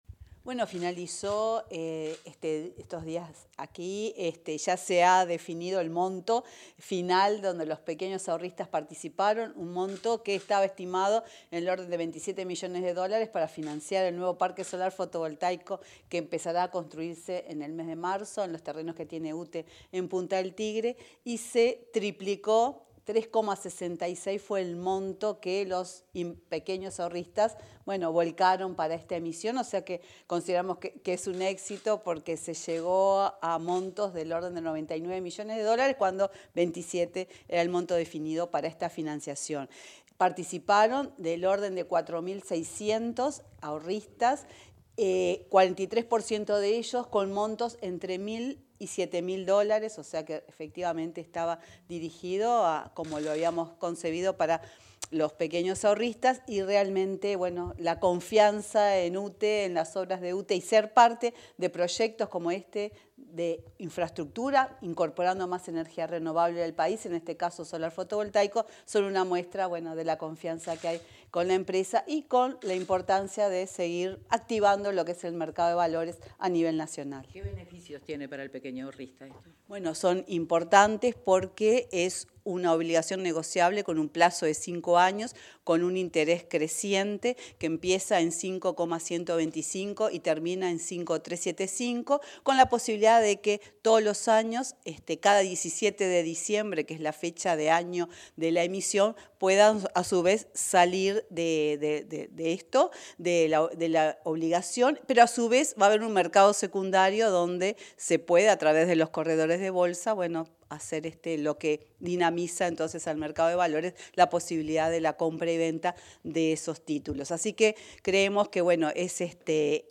Declaraciones de la presidenta de UTE, Silvia Emaldi, a la prensa